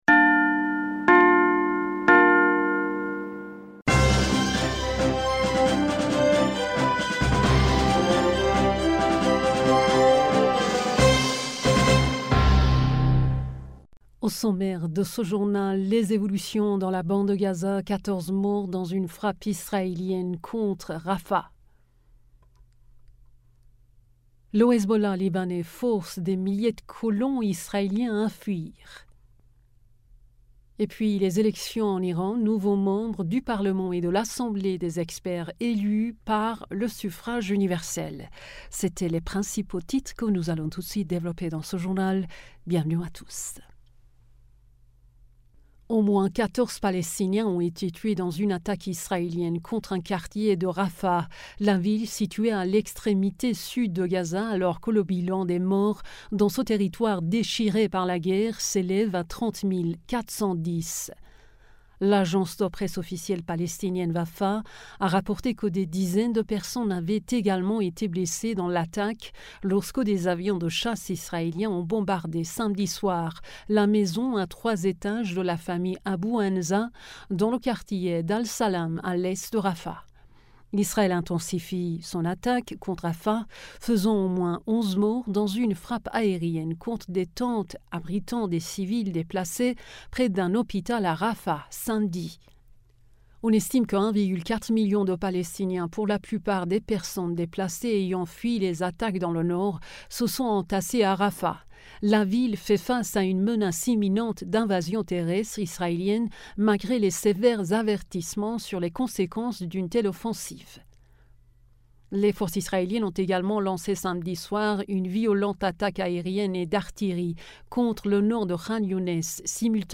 Bulletin d'information du 03 Mars 2024